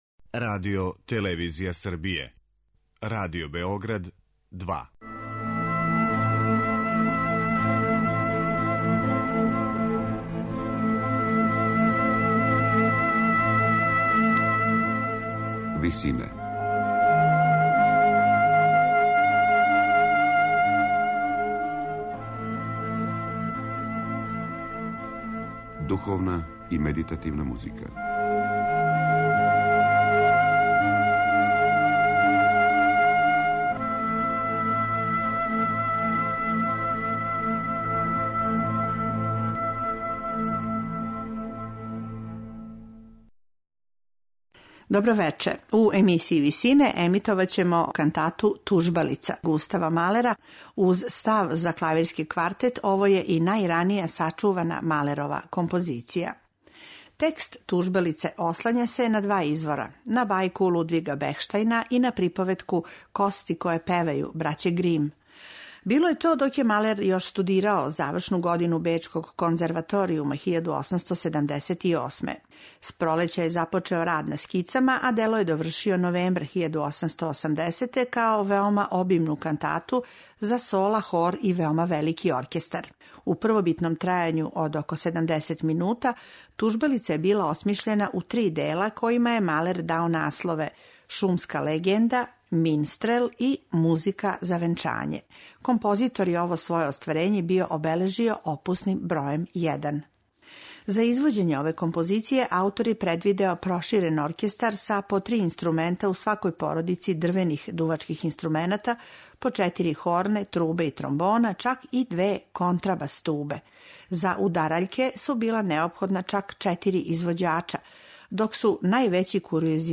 Емитоваћемо мало познато рано дело Густава Малера, кантату „Тужбалица'.